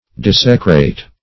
Desecrate \Des"e*crate\, v. t. [imp. & p. p. Desecrated; p.